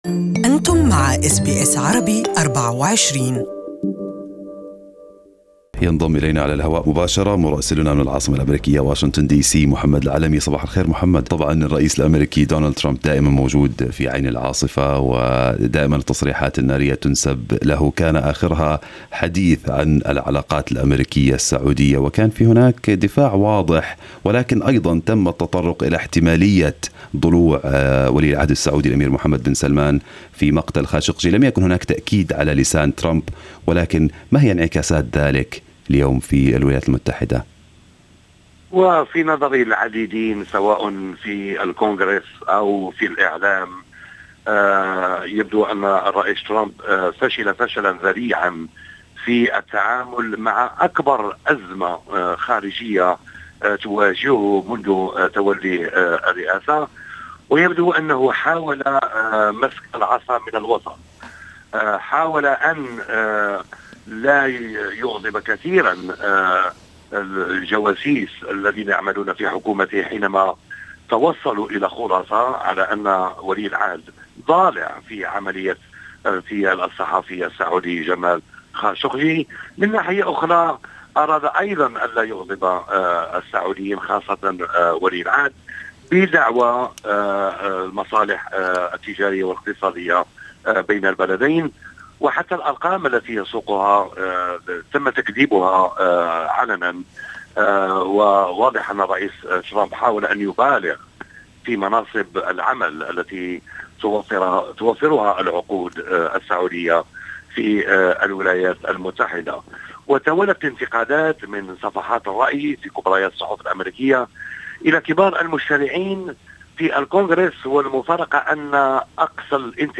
الاستماع لرسالة واشنطن بالكامل في الرابط أعلاه استمعوا هنا الى البث المباشر لإذاعتنا و إذاعة BBC أيضا حمّل تطبيق أس بي أس الجديد على الأندرويد والآيفون للاستماع لبرامجكم المفضلة باللغة العربية شارك